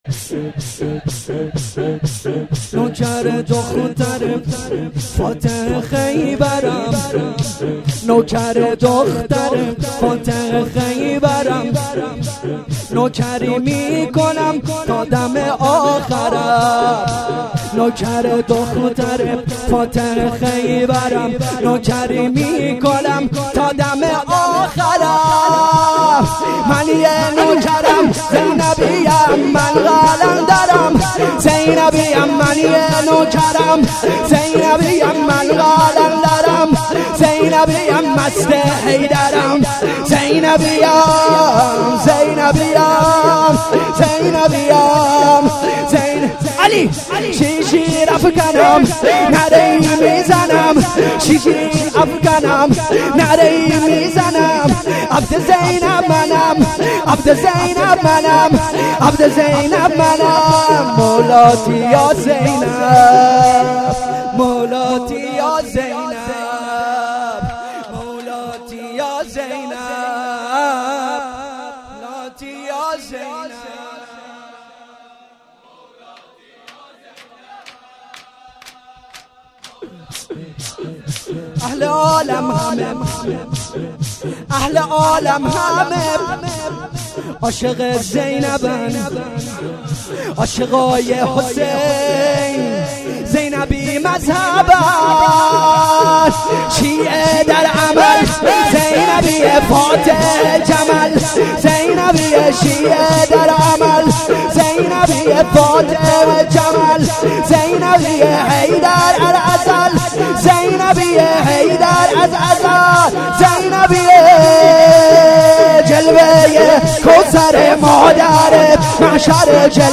مراسم هفتگی مجمع رهروان حضرت زینب س